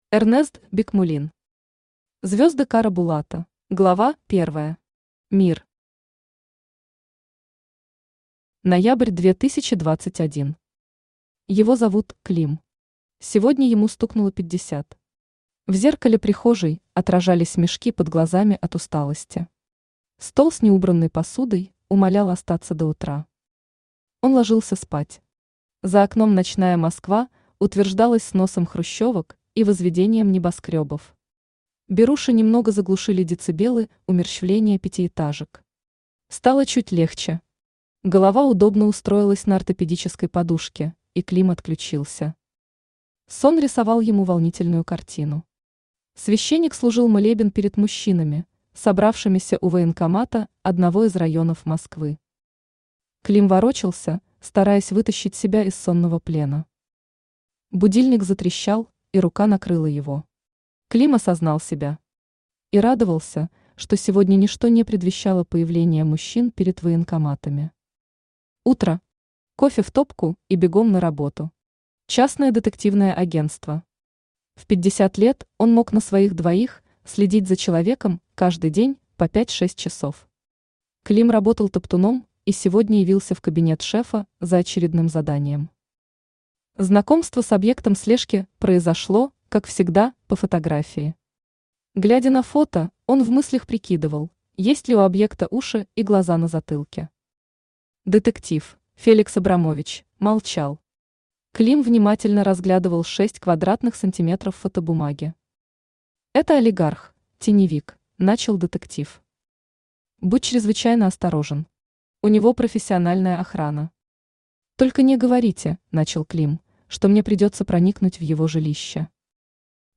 Аудиокнига Звезды Кара-Булата | Библиотека аудиокниг
Aудиокнига Звезды Кара-Булата Автор Эрнест Бикмуллин Читает аудиокнигу Авточтец ЛитРес.